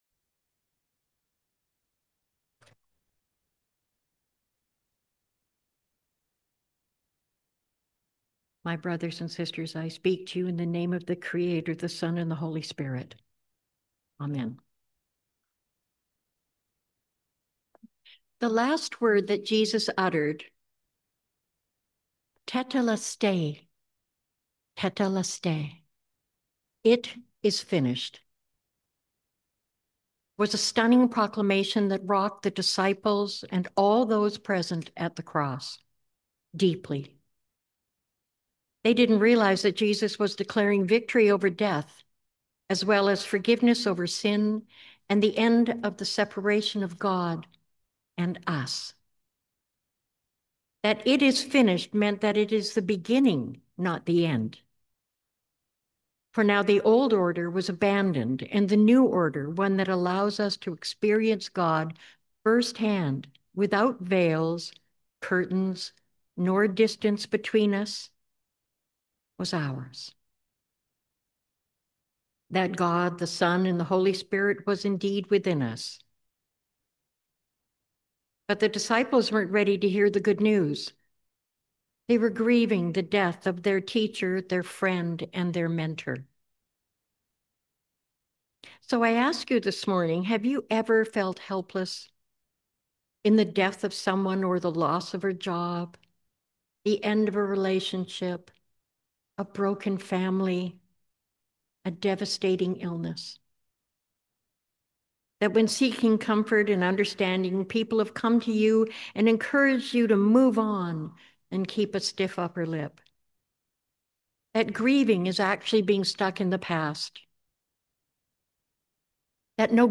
Sermon on Good Friday